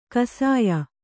♪ kasāya